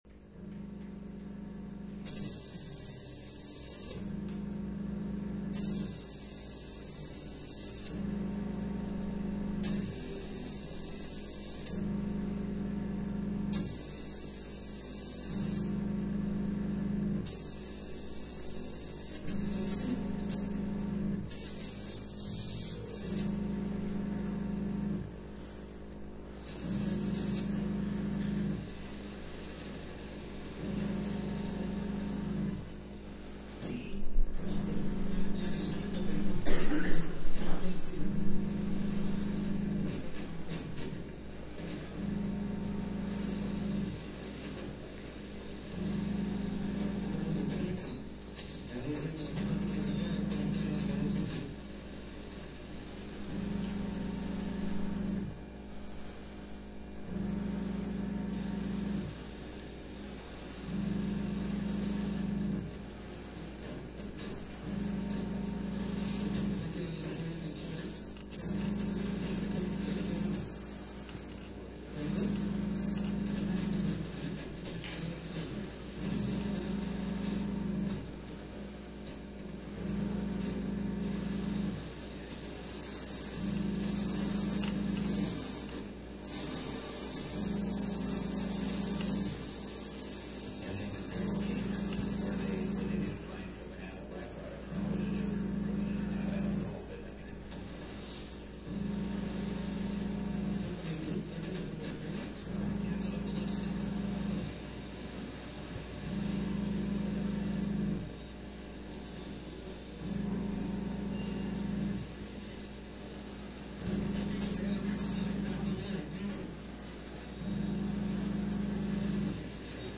Shortwave